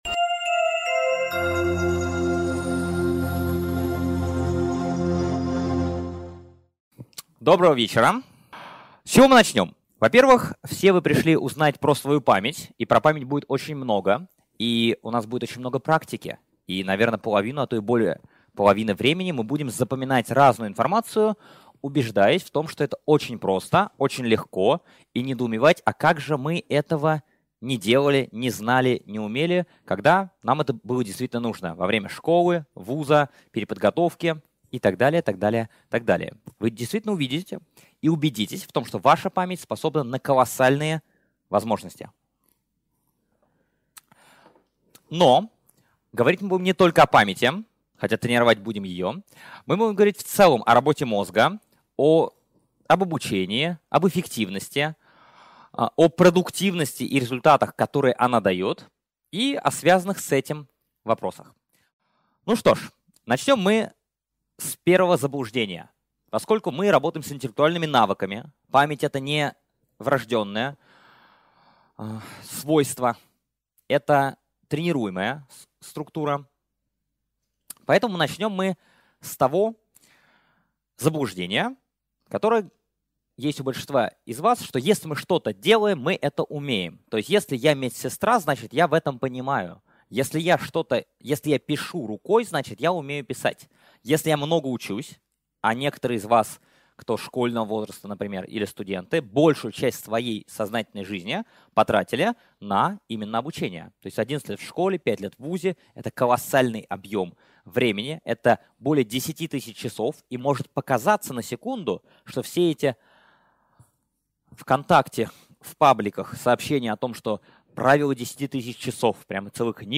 Аудиокнига Как выучить английский за 3 месяца | Библиотека аудиокниг